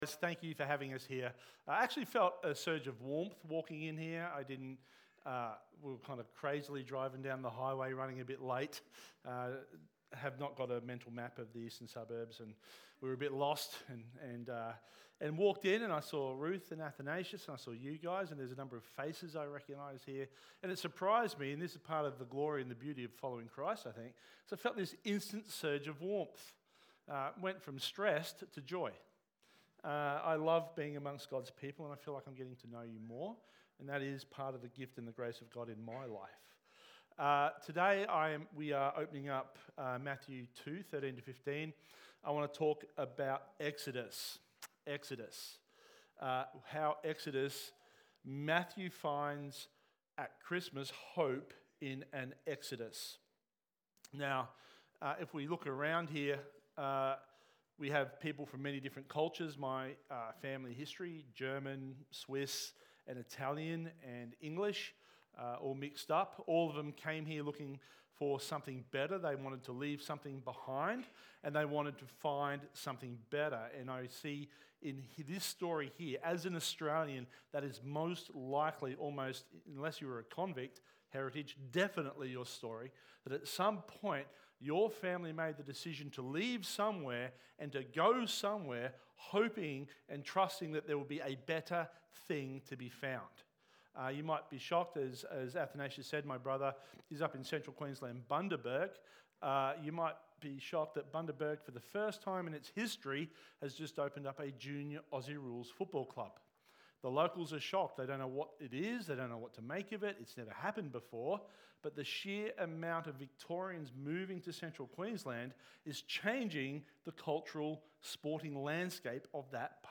Church-Sermon-211225.mp3